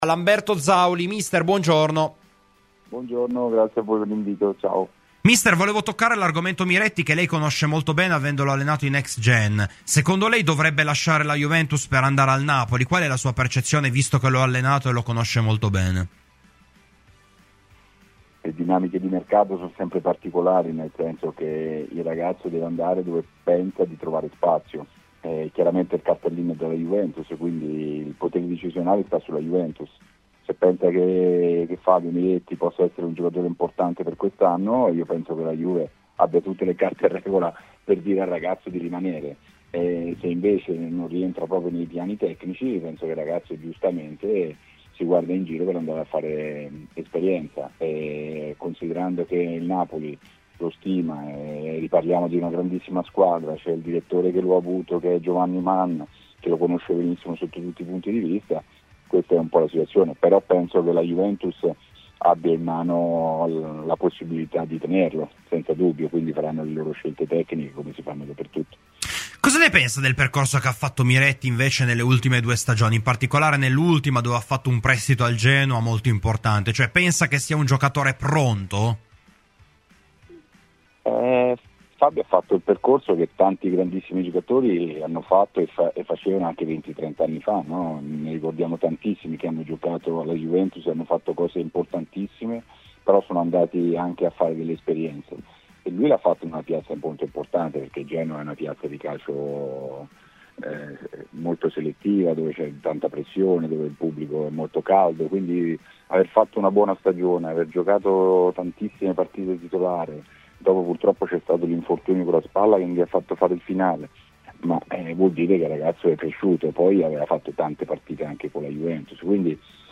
Ospite della "Rassegna Stramba" di Radio Bianconera, l'ex tecnico della Juventus Next Gen Lamberto Zauli ha parlato della possibilità che Fabio Miretti lasci la Juventus per andare al Napoli: "Il ragazzo deve andare dove pensa di trovare spazio, ma il cartellino è della Juventus.